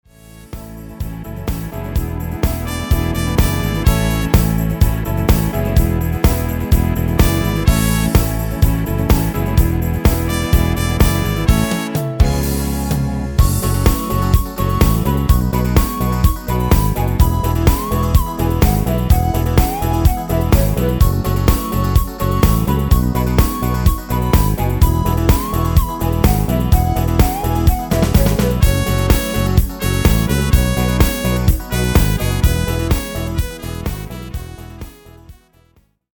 Tyros5